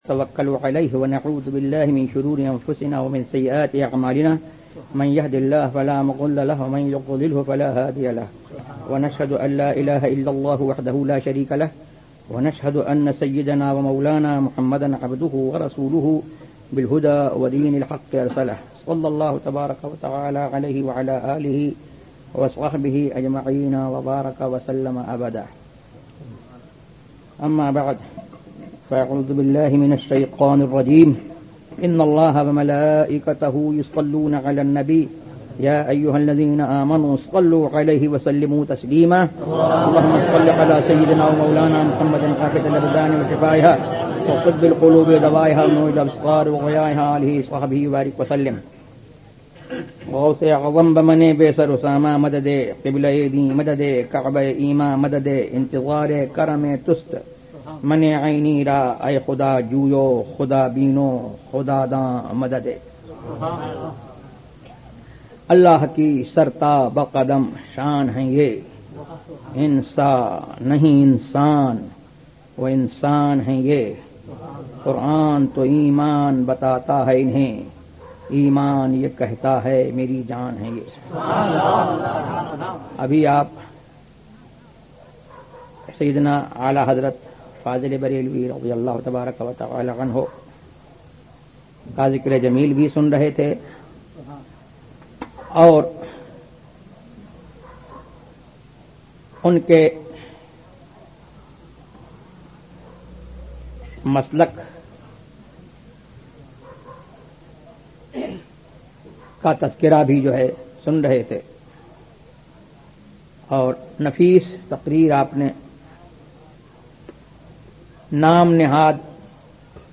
تقاریر